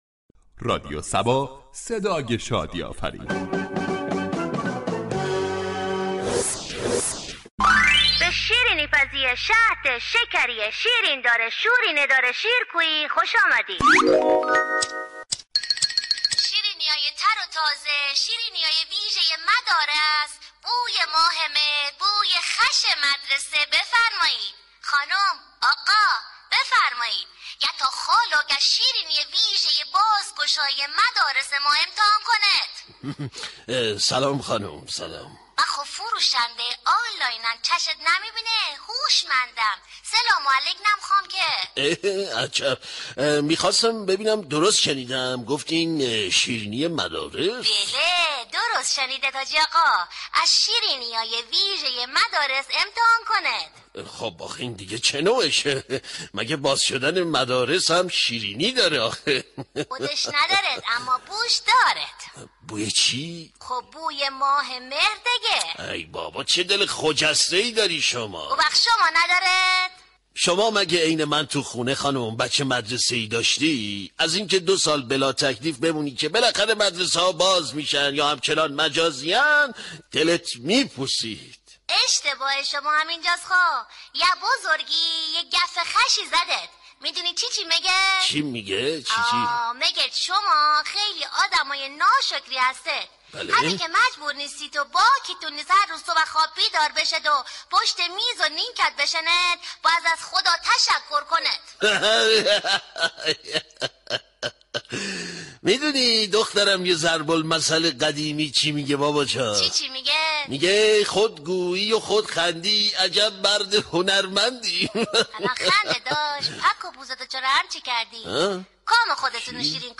در بخش نمایشی شهر فرنگ با بیان طنز به موضوع بازگشایی مدارس پرداخته شده است ،در ادامه شنونده این بخش باشید.